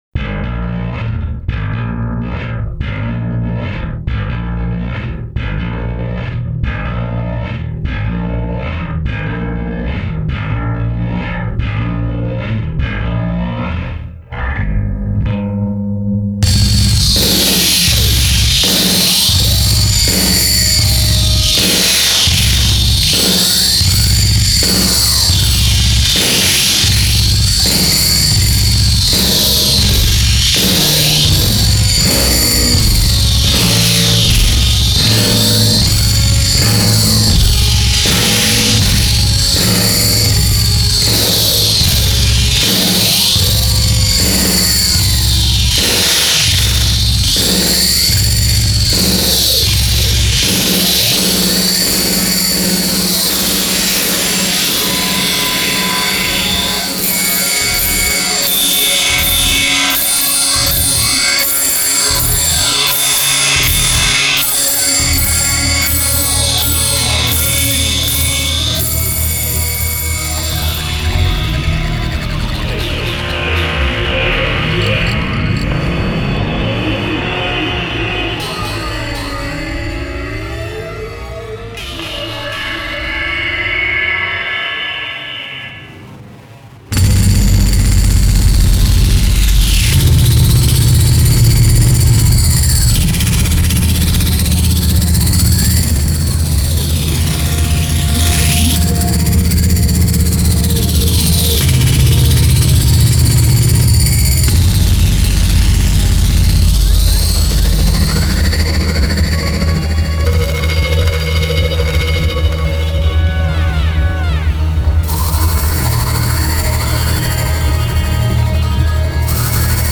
Techno/Industrial